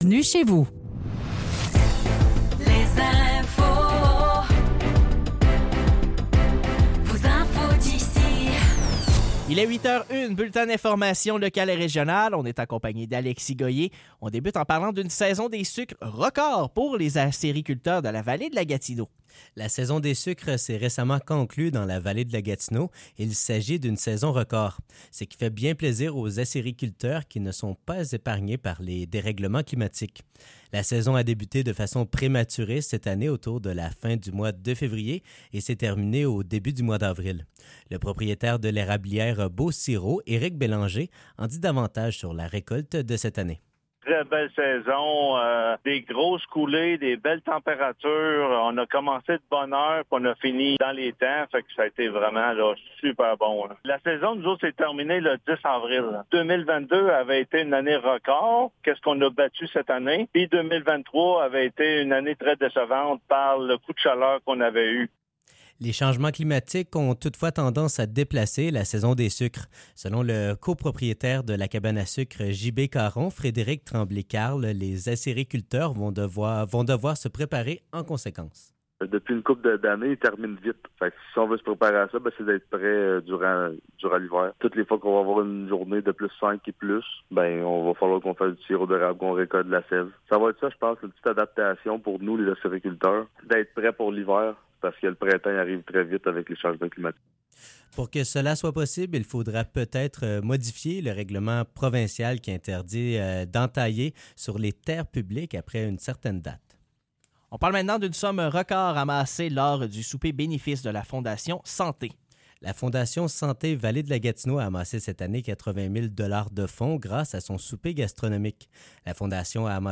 Nouvelles locales - 23 avril 2024 - 8 h